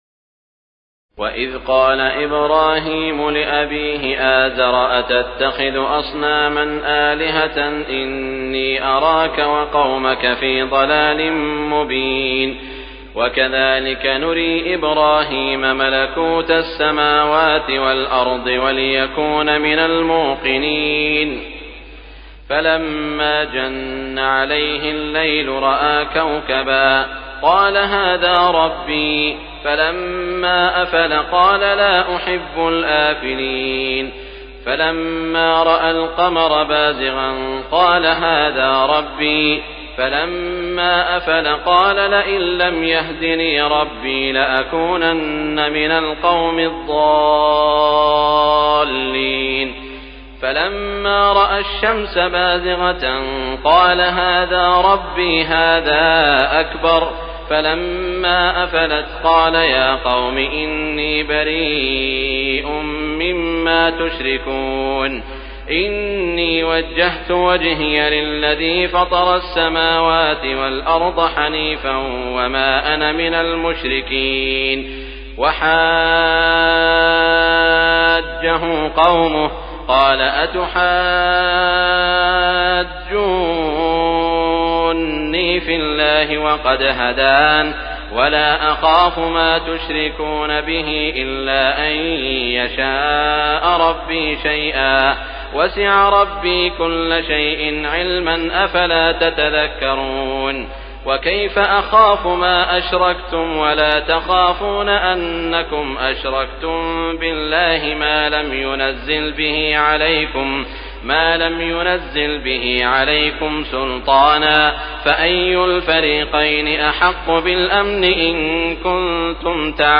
تراويح الليلة الثامنة رمضان 1418هـ من سورة الأنعام (74-165) Taraweeh 8 st night Ramadan 1418H from Surah Al-An’aam > تراويح الحرم المكي عام 1418 🕋 > التراويح - تلاوات الحرمين